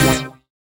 Synth Stab 11 (C).wav